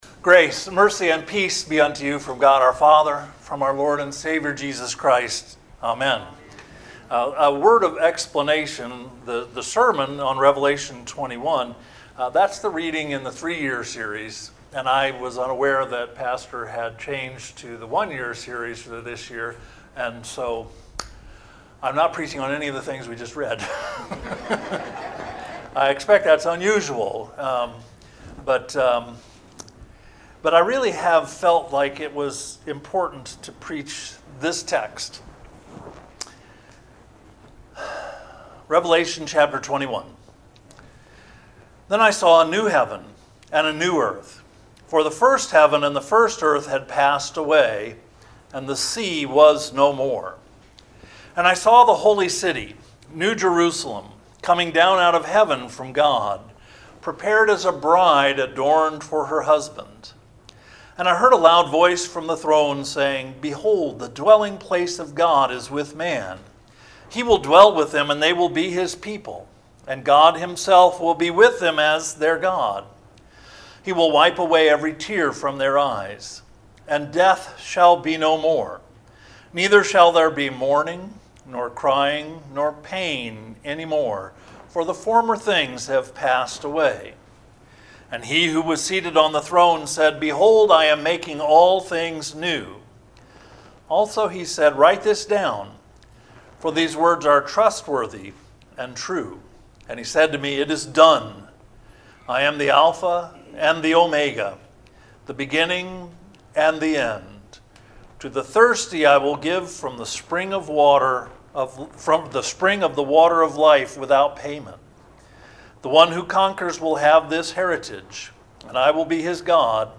NOTE: THE SERMON IS MP3 PLAYER AUDIO ONLY SERMON, JUST CLICK THE BUTTON BELOW.